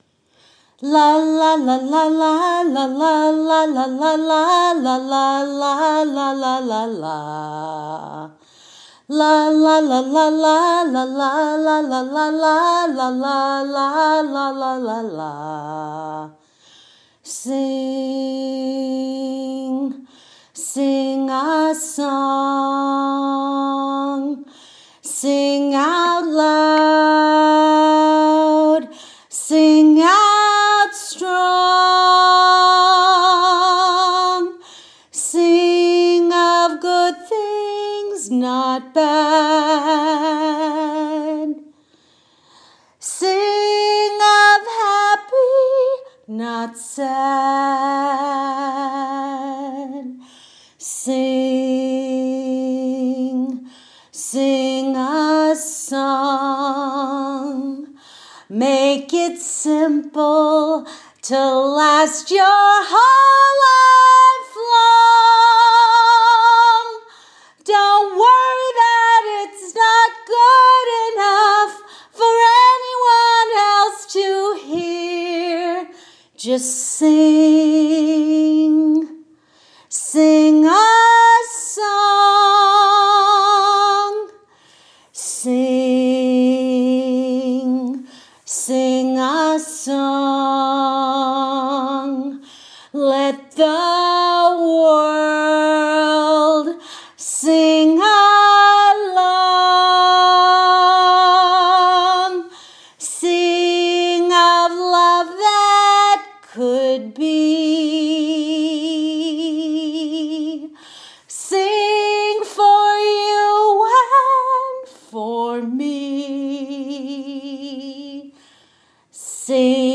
Third take. No edits. No tuning.